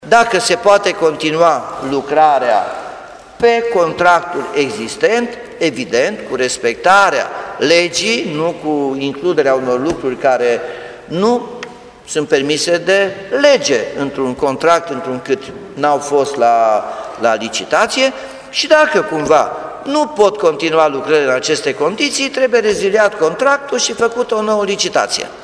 Primarul a spus că există chiar posibilitatea rezilierii contractului de lucrări și desemnării unei alte firme care să execute lucrarea: